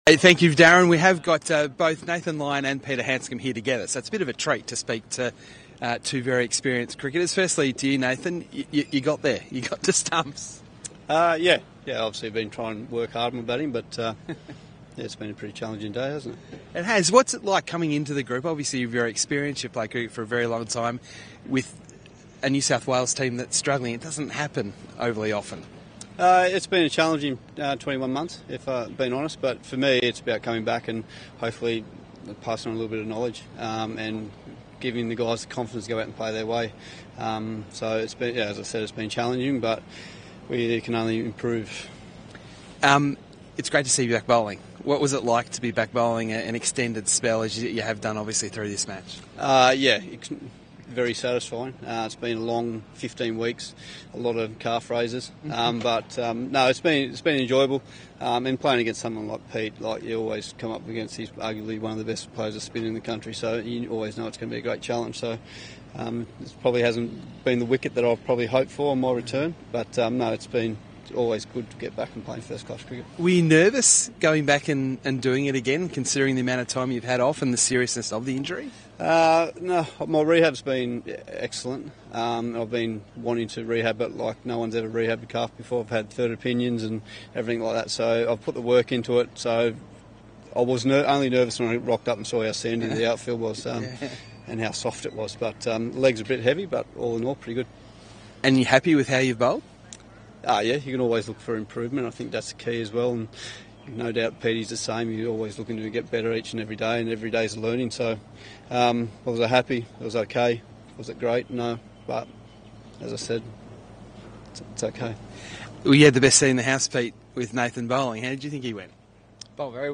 NSW’s Nathan Lyon (1/49) & VIC’s Peter Handscomb (90) speak post match after Day 3